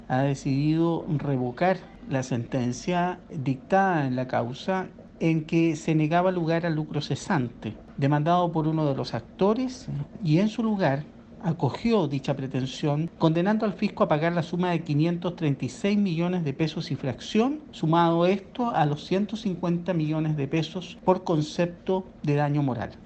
En conversación con Radio Bío Bío, el secretario de la Corte Suprema, Jorge Saéz, afirmó que revocaron la sentencia de segunda instancia e incluyeron el lucro cesante, con un total de 1.286 millones de pesos.